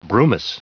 Prononciation du mot brumous en anglais (fichier audio)
Prononciation du mot : brumous